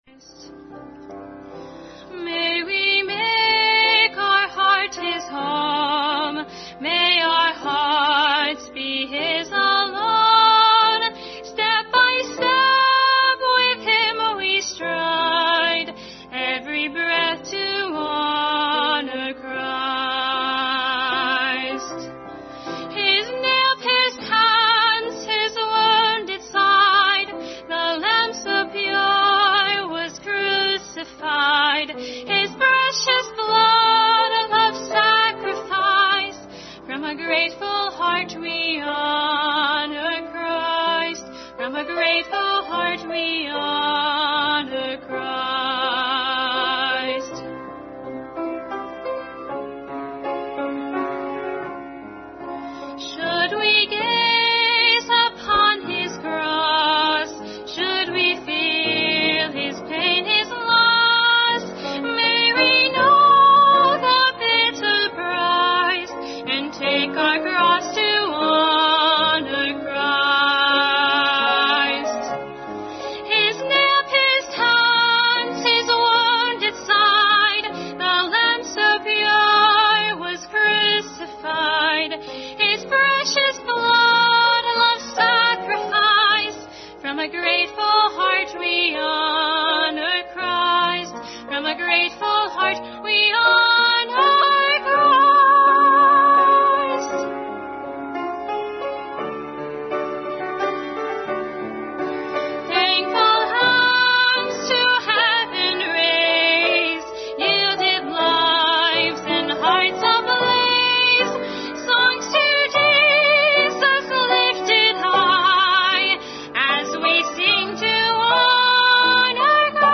Passage: James 4 Service Type: Family Bible Hour